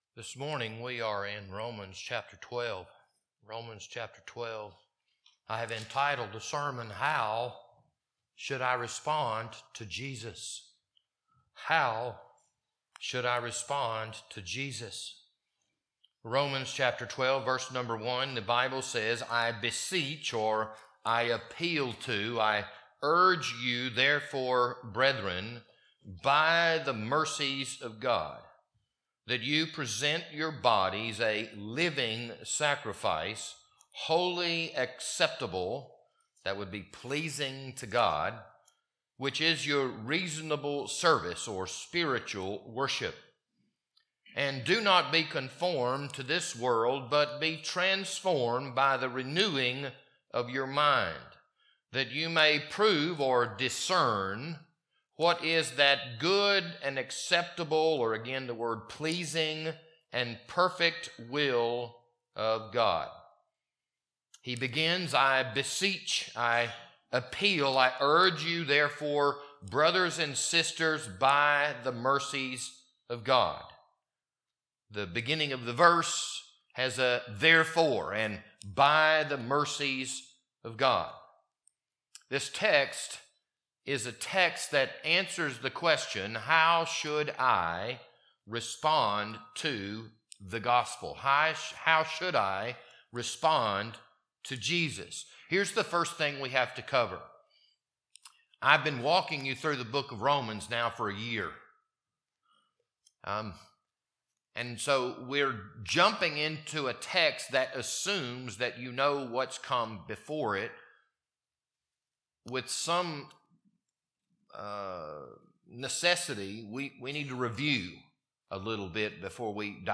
This Sunday morning sermon was recorded on March 16th, 2025.